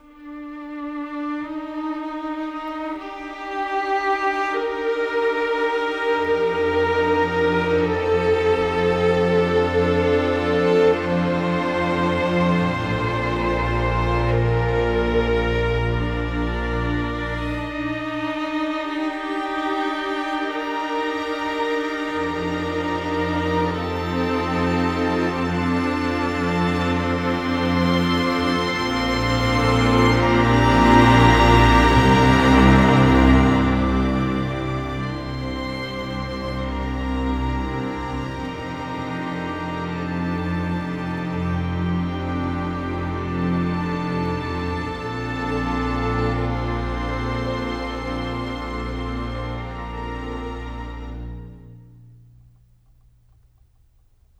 Rock-Pop 17 Strings 05.wav